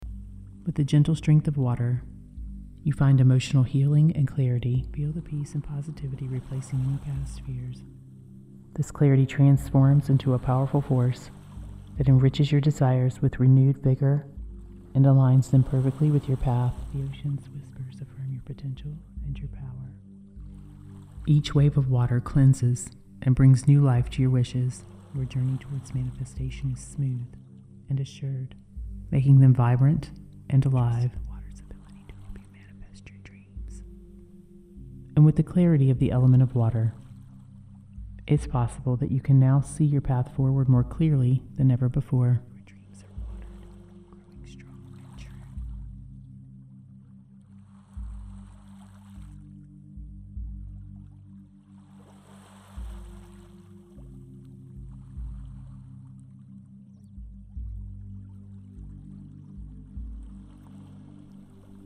This guided meditation session uses advanced spatial audio techniques to create a vivid, all-encompassing experience of the elements. (Headphones required for the 3d sound.)
This immersive auditory experience goes beyond traditional stereo techniques by adding height and depth to the soundscape, which maximizes engagement and enhances the perceptual impact of the session.
Spatial Layering of Hypnotic Suggestions: The hypnotic suggestions are delivered across multiple spatial layers, actively engaging your subconscious mind in diverse ways.
Theta Wave Integration: The inclusion of a theta wave track deepens relaxation and strengthens your connection with your subconscious mind, optimizing your mental state to absorb the suggestions for an even more powerful effect on your ability to manifest with precision.